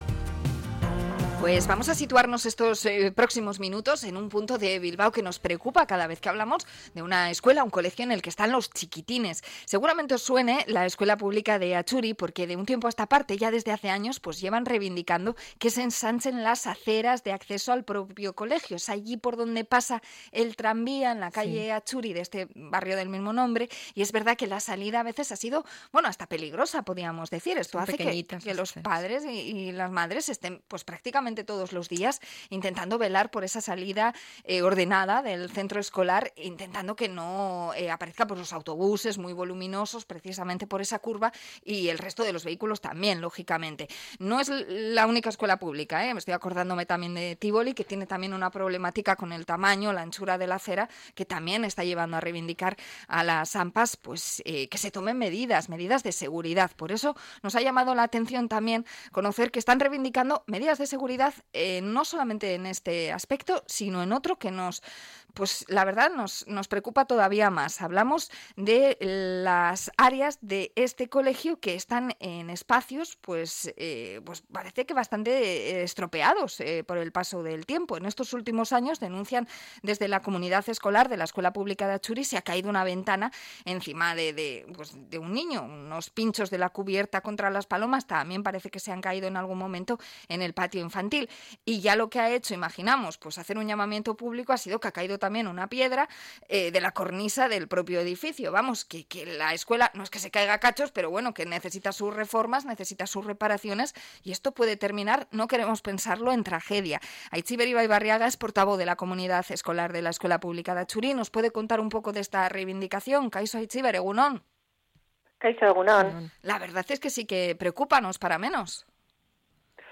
Entrevista a la comunidad escolar de Atxuri por la falta de mantenimiento de la escuela